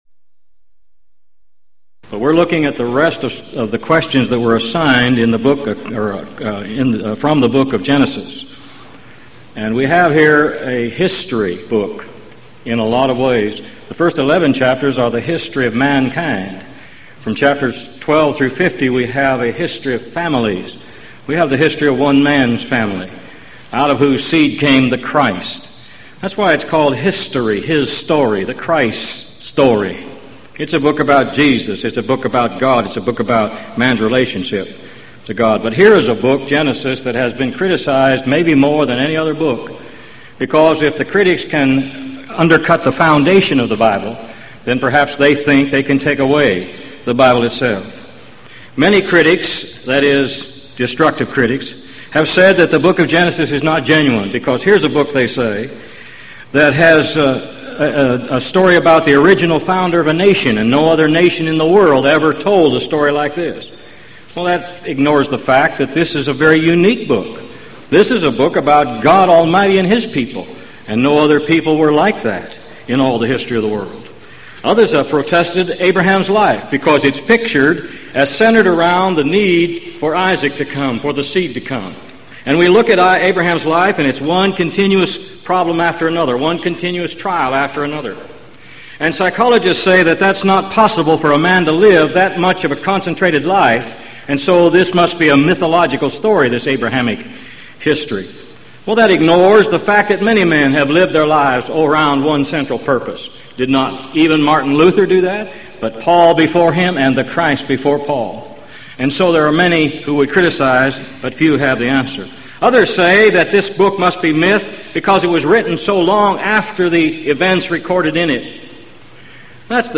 Event: 1995 Gulf Coast Lectures Theme/Title: Answering Alleged Contradictions & Problems In The Old Testament
lecture